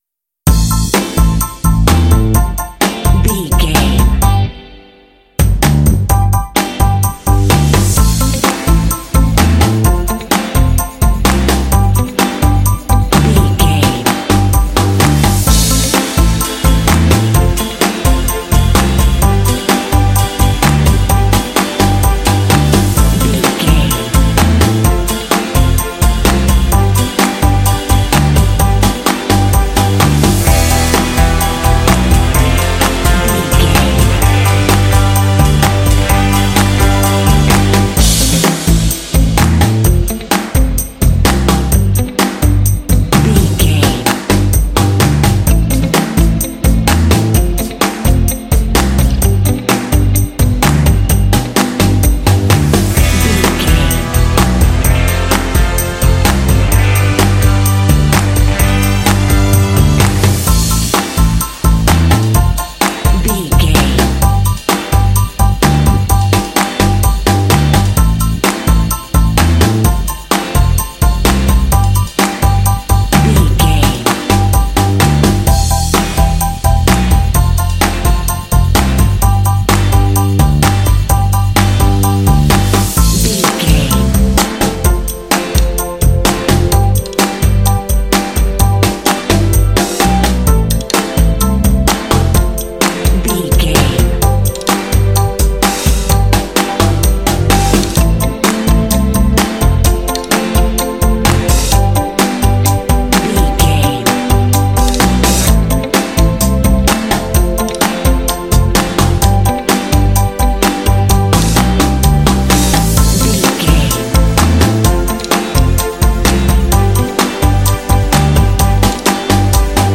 Uplifting
Aeolian/Minor
bouncy
groovy
piano
electric guitar
bass guitar
drums
strings
indie
rock
contemporary underscore